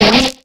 Cri de Ningale dans Pokémon X et Y.